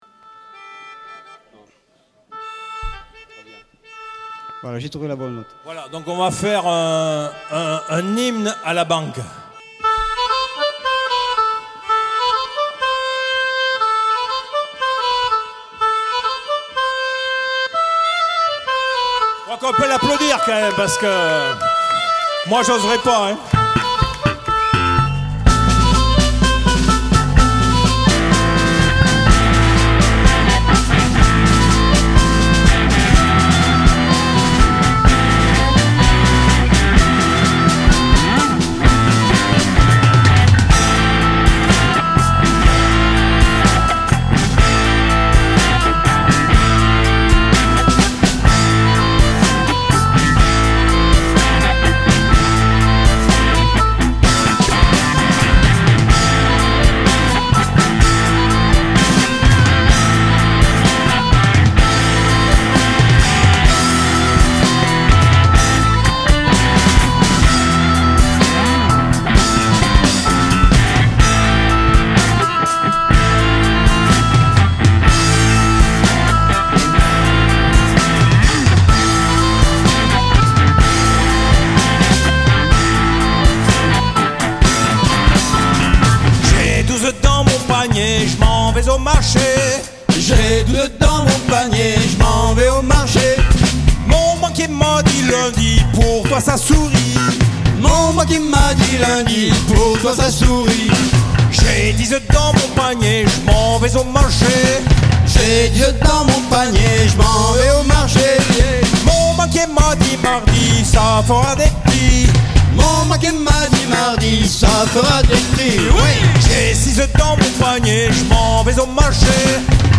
enregistré au Lembarzique
à l'occasion des Mardynamiques